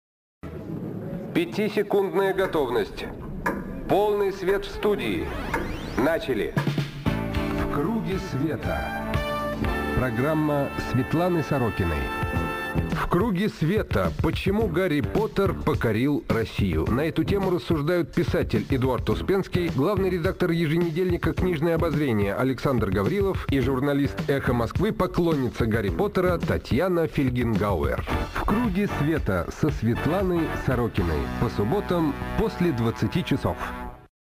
на радио «Эхо Москвы»
соведущий - Юрий Кобаладзе
Аудио: анонс –